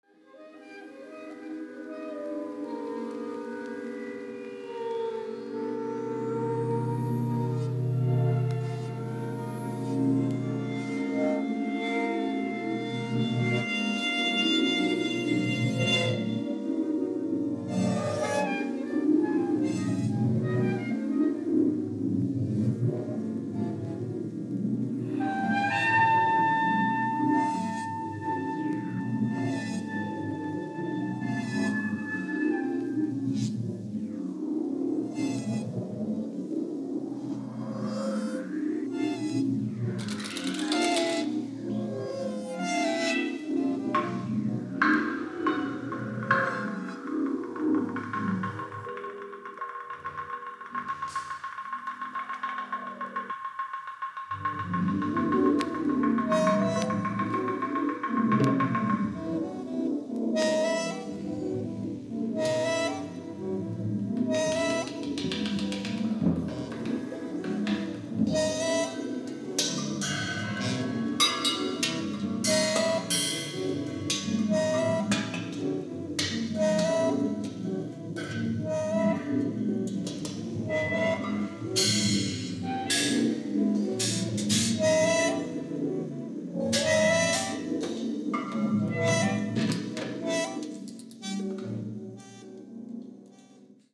Vocals, Piano